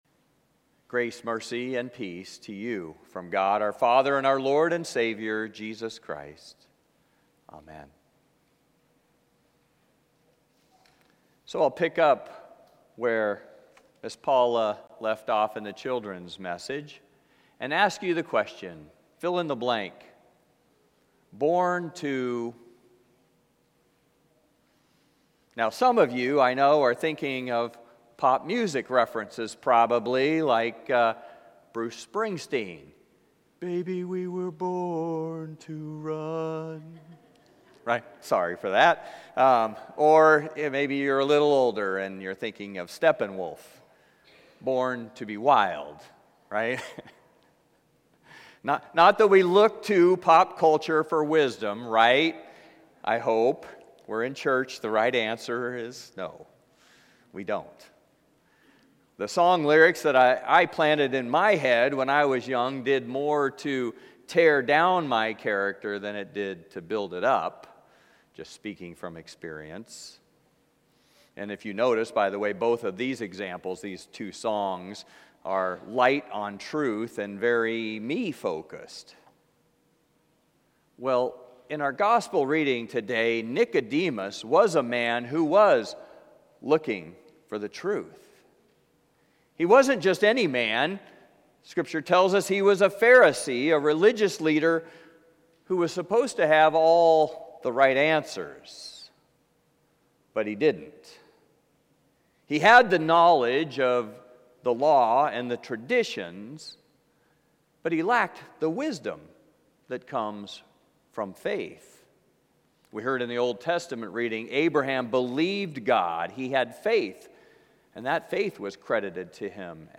Passage: John 3:1-17 Service Type: Traditional and Blended « Lent Midweek 1